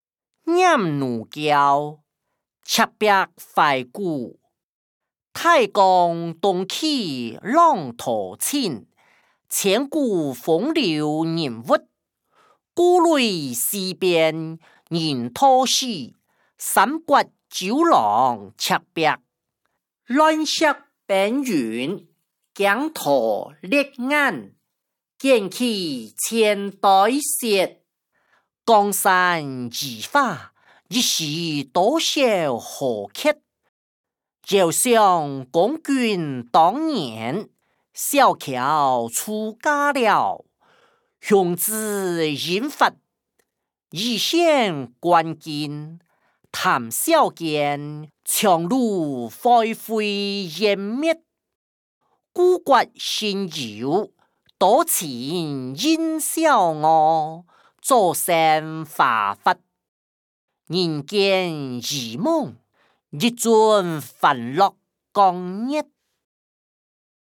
詞、曲-念奴嬌•赤壁懷古音檔(大埔腔)